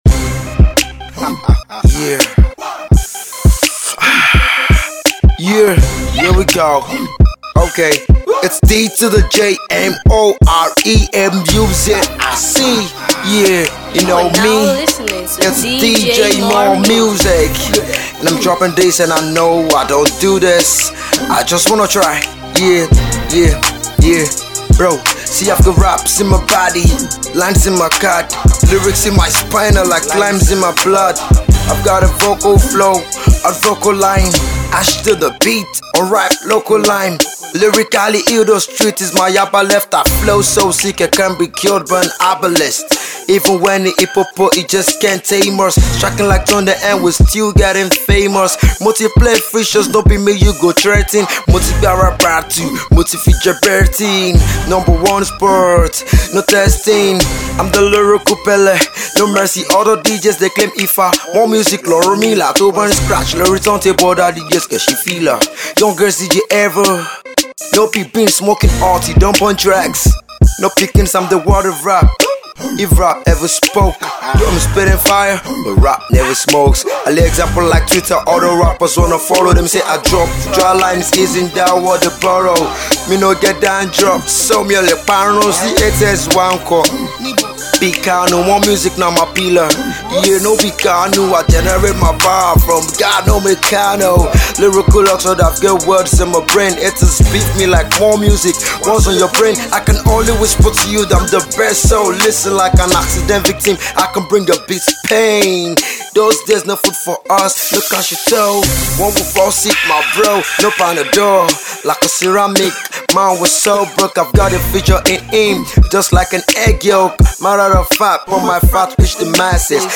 Rap …